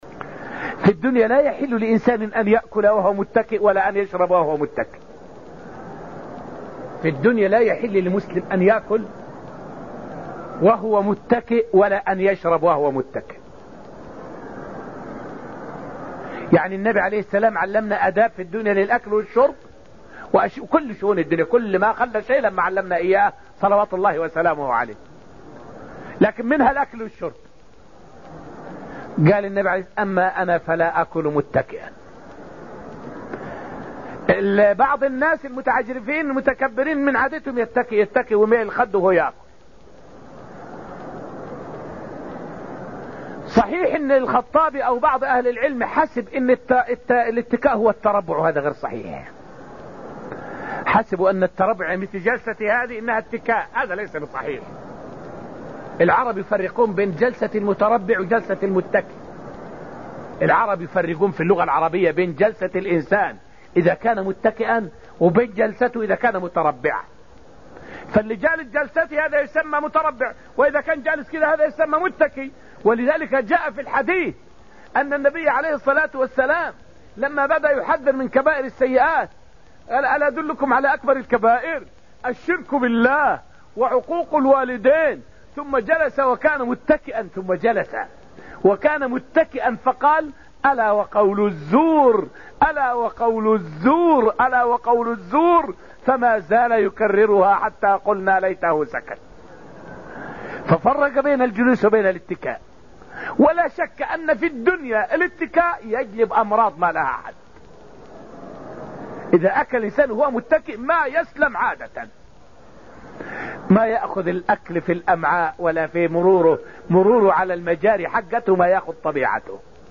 فائدة من الدرس الرابع من دروس تفسير سورة الطور والتي ألقيت في المسجد النبوي الشريف حول بيان النهي عن الأكل والشرب متكئًا.